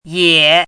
chinese-voice - 汉字语音库
ye3.mp3